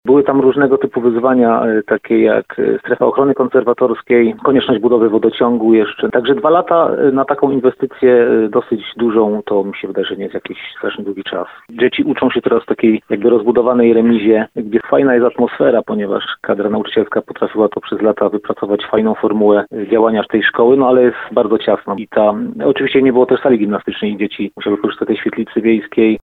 Nie ma też sali gimnastycznej, a dzieci musiały korzystać ze świetlicy wiejskiej – mówi Rafał Kamieński, radny gminy Kamionka Wielka.